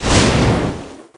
bar_fire_atk_01.ogg